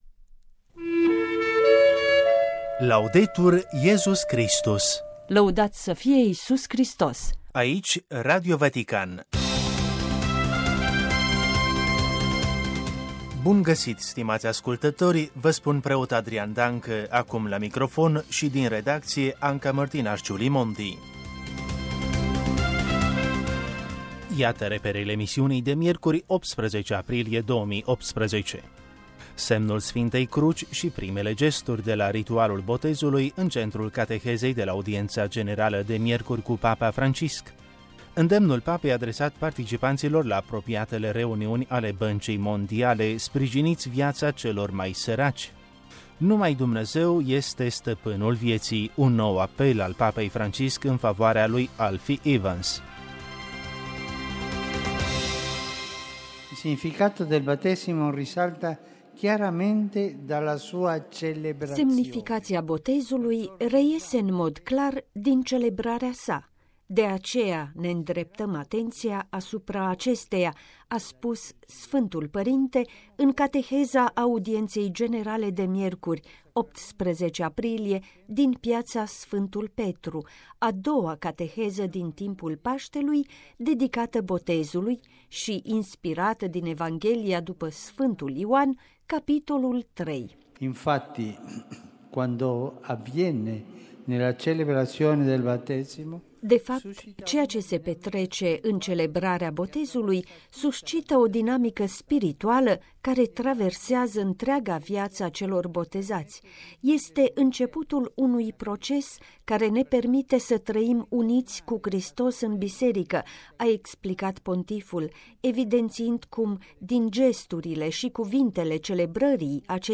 Radio Vatican. Buletinul de știri al zilei: 18 aprilie 2018